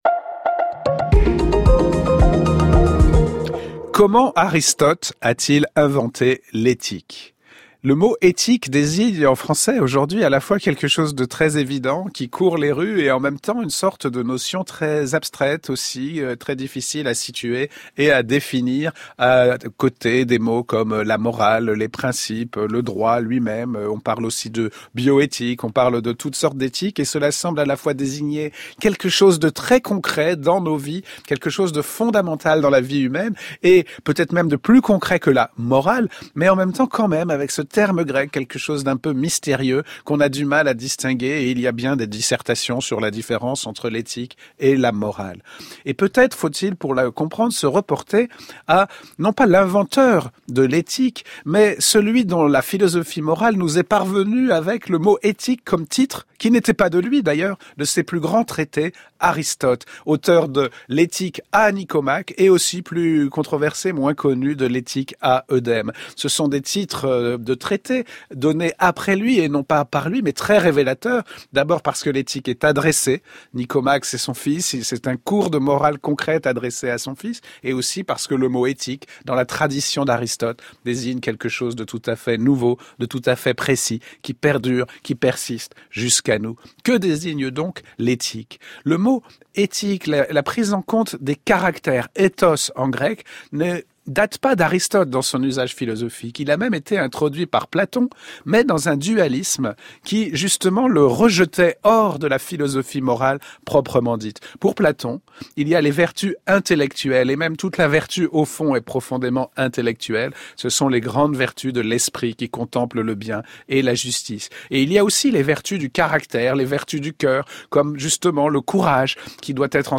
Émission de radio